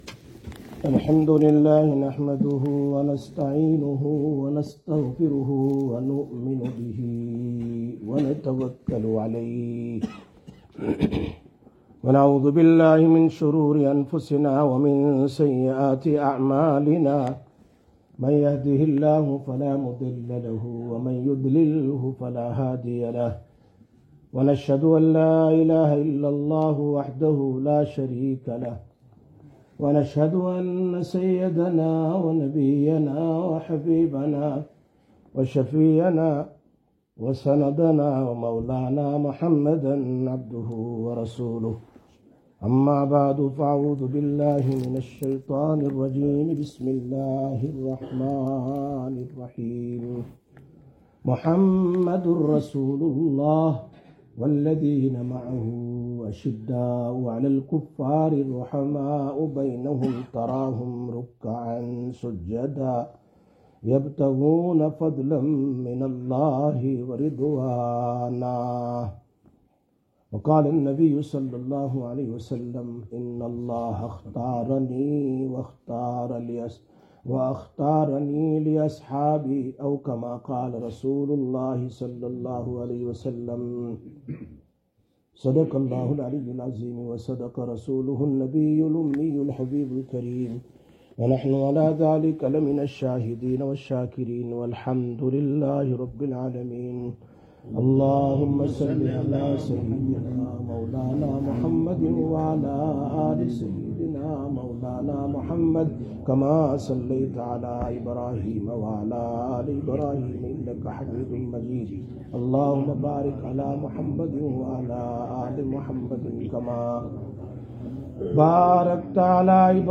01/08/2025 Jumma Bayan, Masjid Quba